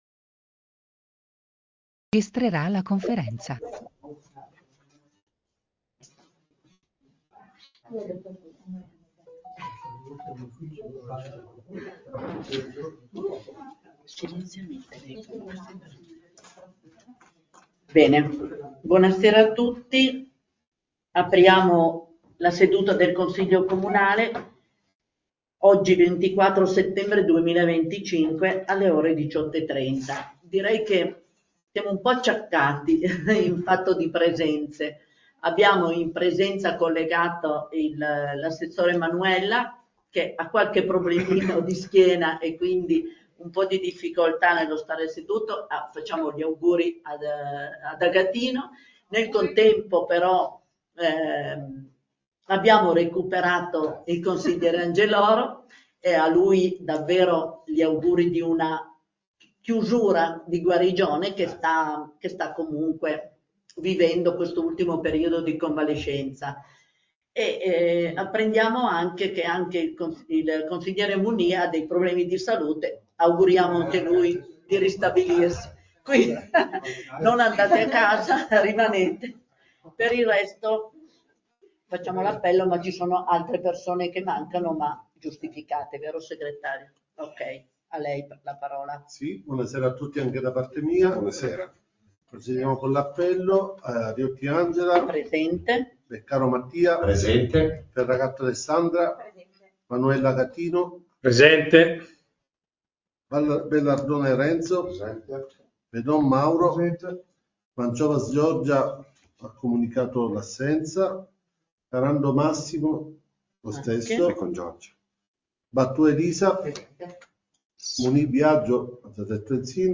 Comune di Santhià - Registrazioni audio Consiglio Comunale - Registrazione Seduta Consiglio Comunale 24/09/2025